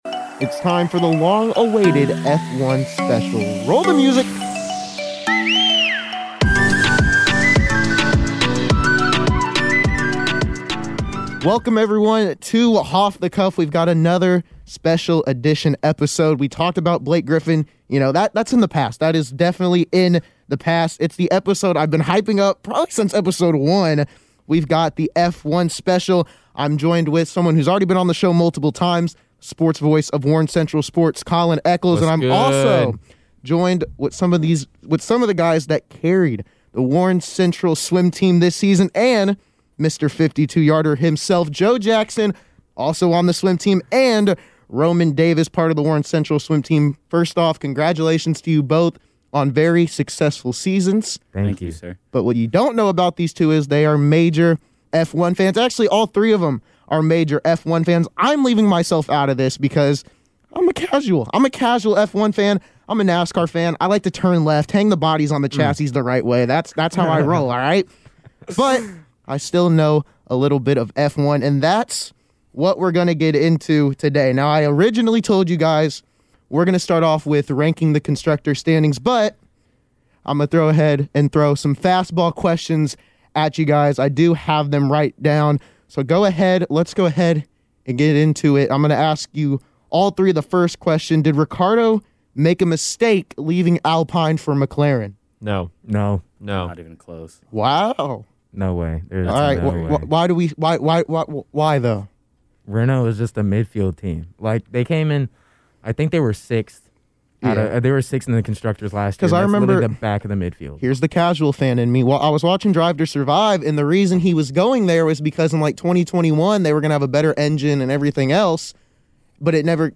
As the F1 season nears closer got 3 of the biggest F1 fans I know and we get into all the offseason moves and....... well I wont spoil it just tune and get to know the craziness that is Formula 1